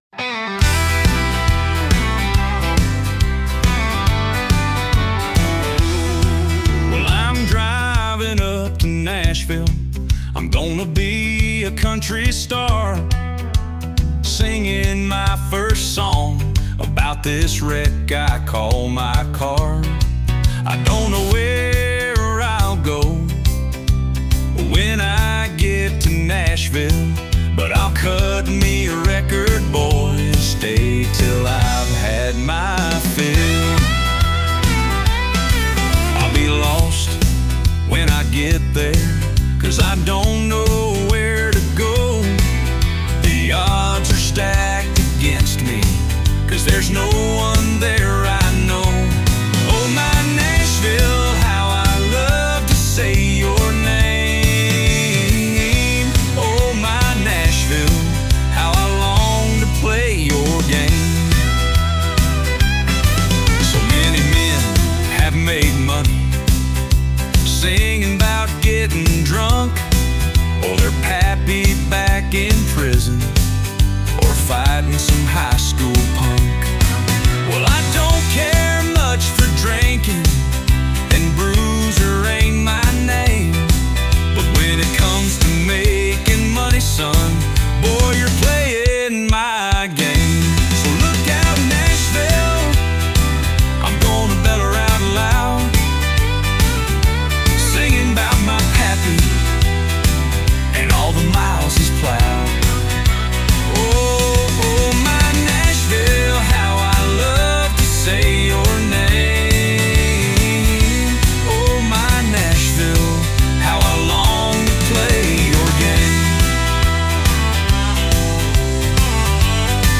It’s a country song that I wrote the lyrics to while driving in my car from Shreveport, Louisiana to Magnolia, Arkansas in 1974 at the age of 26.
Rather than hire a professional, I purchased some AI software and learned how to use the software to write the melody for my lyrics. The software even created an AI-generated voice that proved to be much better than my own voice.